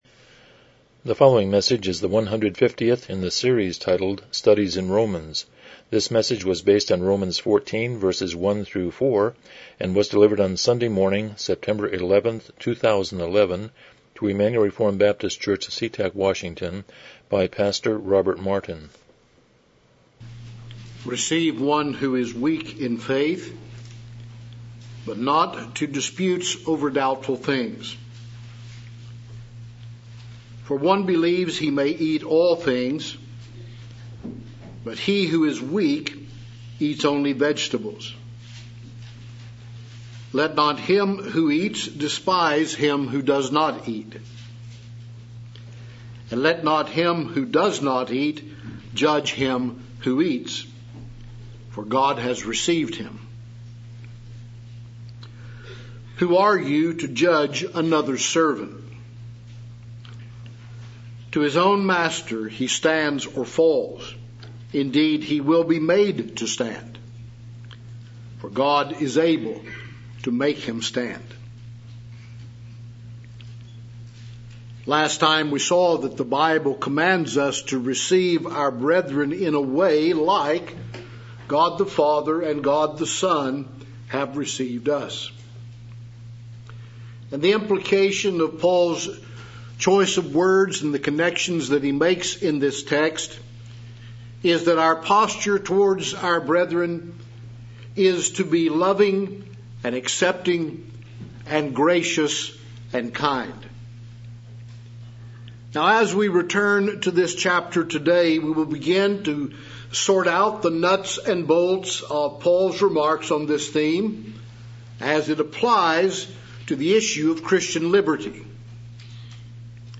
Romans 14:1-4 Service Type: Morning Worship « 128 Chapter 26.4